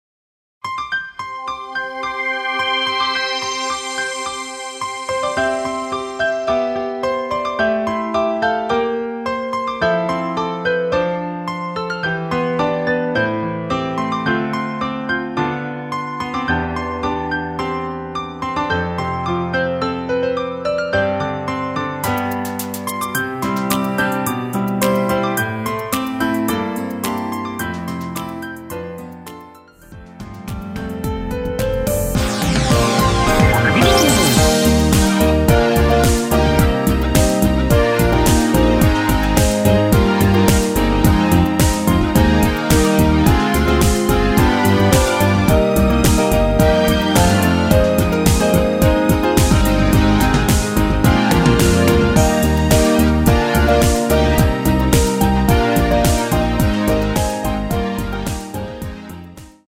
원키가 높아서 여자분이 부르셔도 되는 MR 입니다.